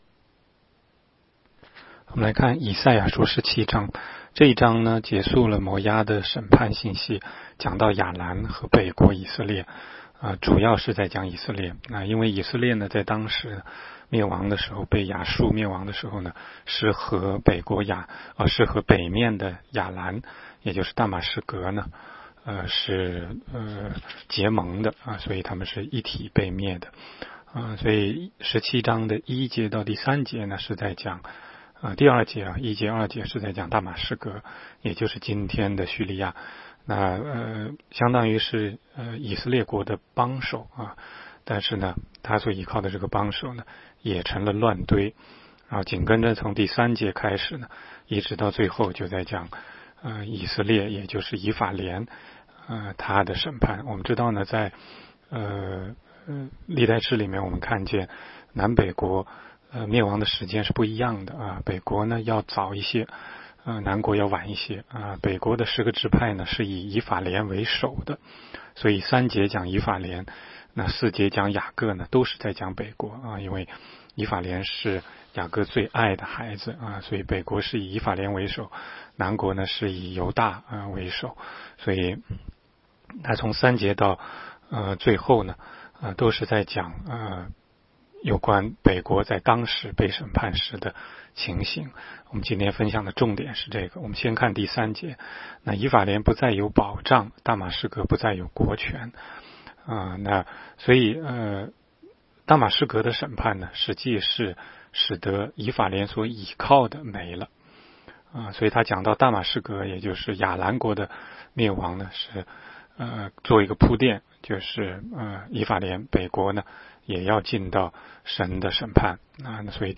16街讲道录音 - 每日读经 -《 以赛亚书》17章